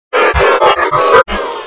Boo
boo.wav